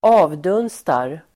Uttal: [²'a:vdun:star]